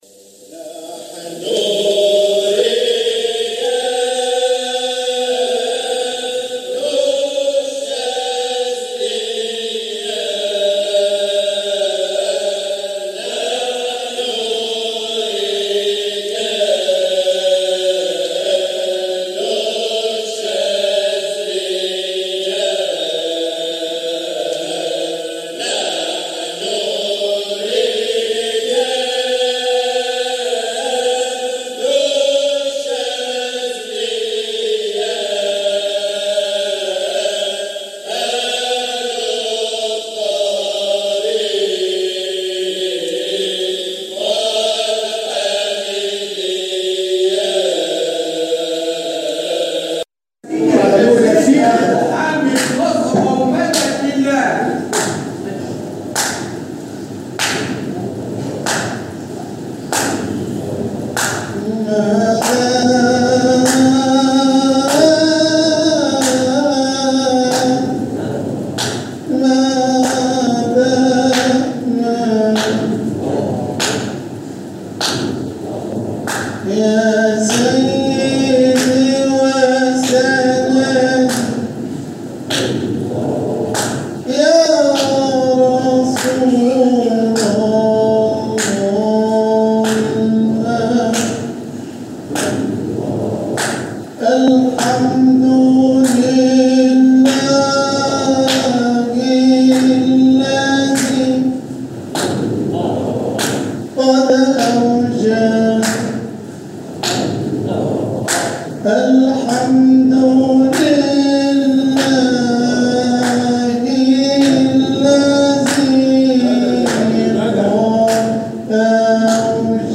جزء من حلقة ذكر بمسجد مولانا المؤسس قُدس سره